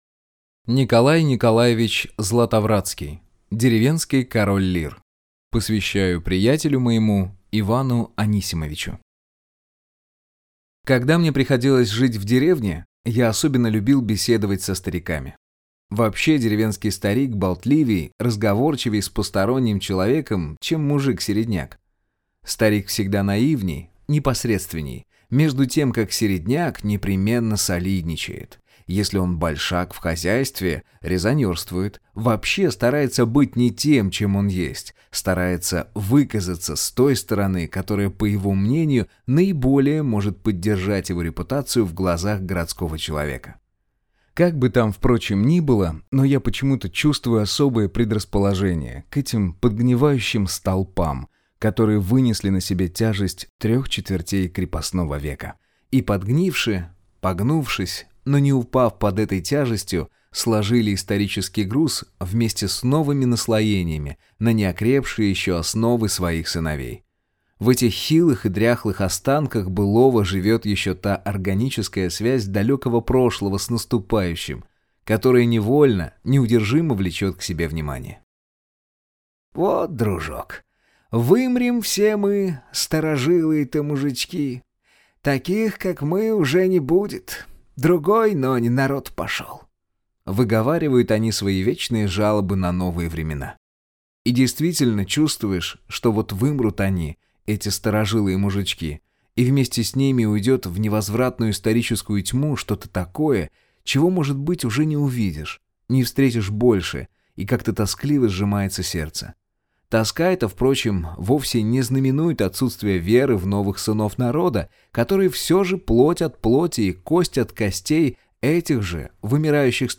Аудиокнига Деревенский король Лир | Библиотека аудиокниг